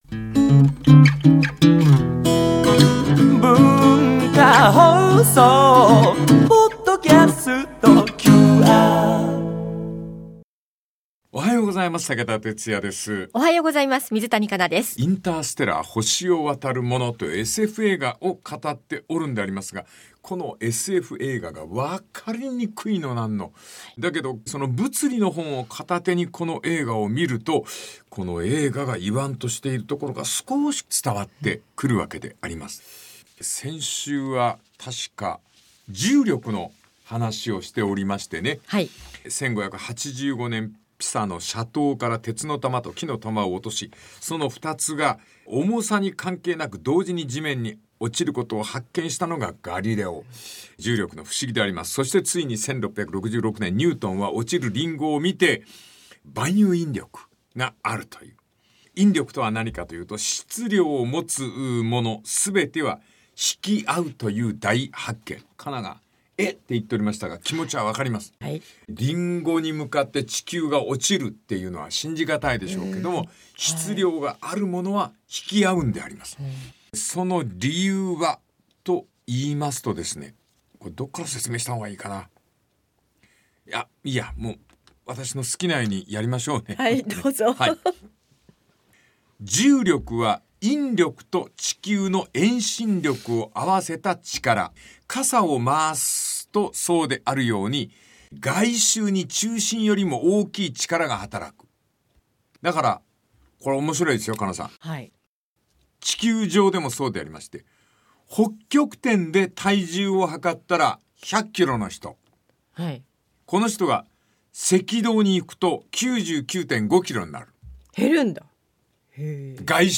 温かさと厳しさを併せ持つ武田鉄矢が毎週テーマに添ってさまざまな語りを展開。